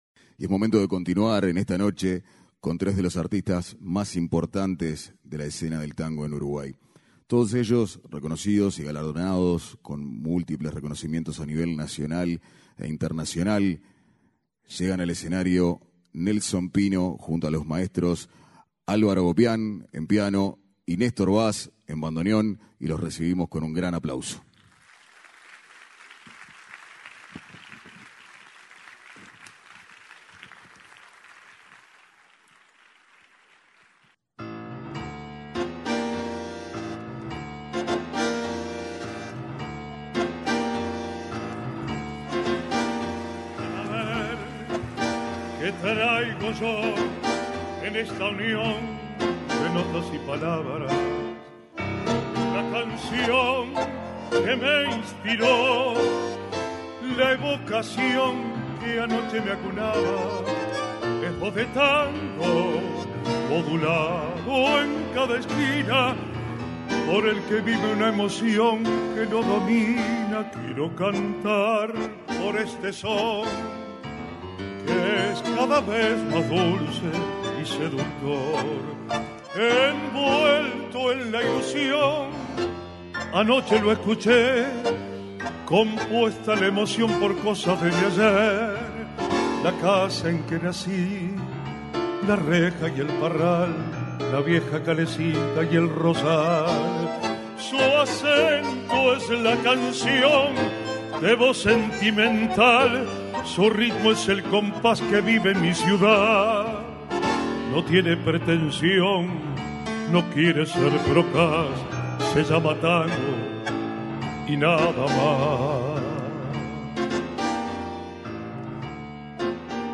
Trasmisión en vivo desde el Auditorio Nelly Goitiño.
bandoneonista
pianista
Tango de la más alta calidad a nivel mundial.